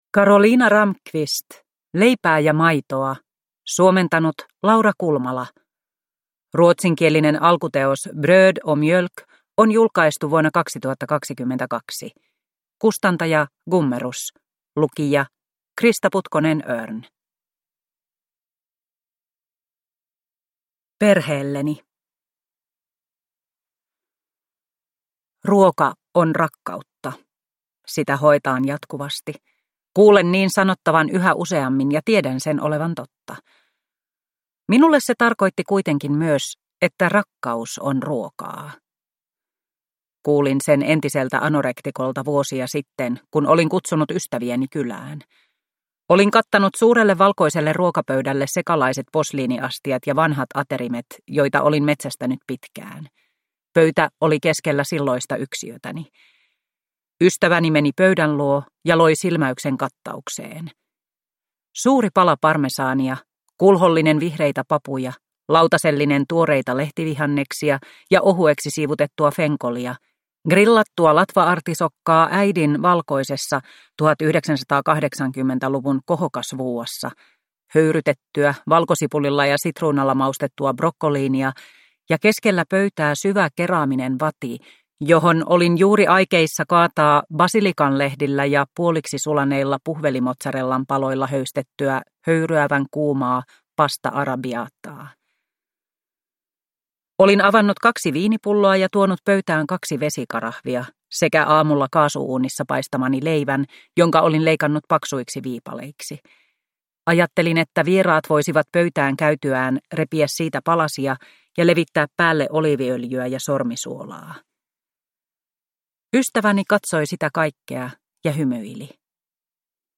Leipää ja maitoa – Ljudbok